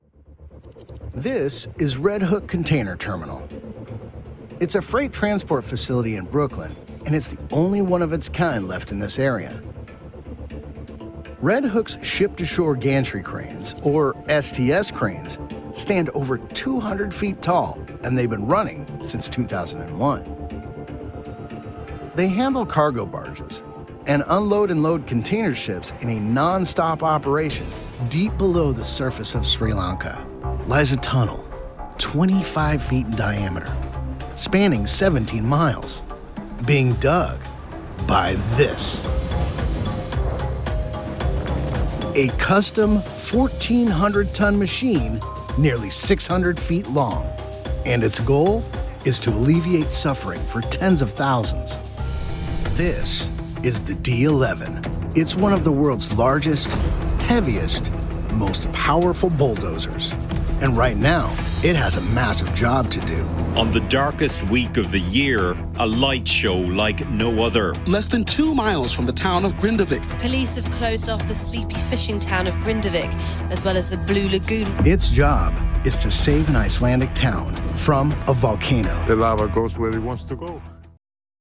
Narration Reel
Middle Aged
I have a broadcast quality home studio and love connecting for directed sessions.